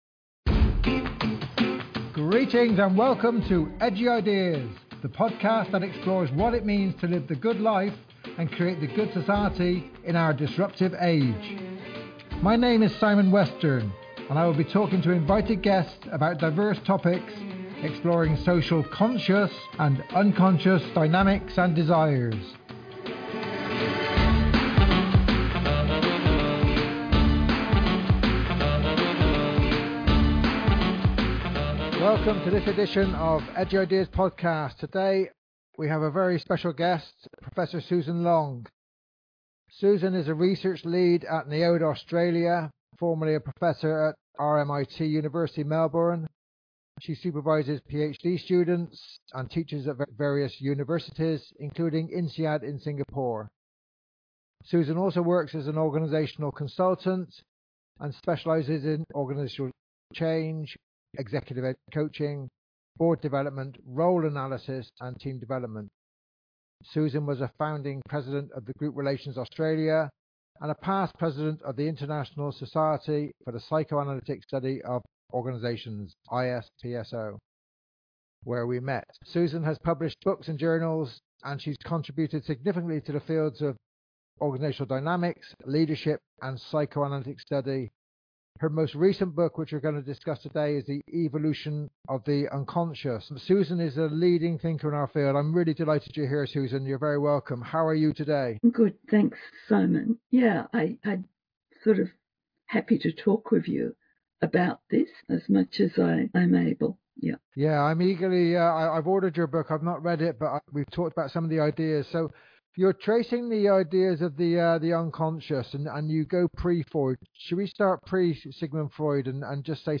She critiques the ideological structures that shape how we perceive the unconscious, drawing on the Frankfurt School’s insights into culture and power. At the heart of this discussion is the notion that creativity—so often seen as an individual gift—actually emerges from the collective unconscious, offering both potential and peril. This conversation invites us to consider the ethical dimension of confronting the unconscious, urging us to move beyond mere self-awareness and towards a deeper responsibility—to ourselves, our communities, and the wider world.